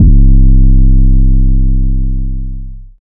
DEEDOTWILL 808 49.wav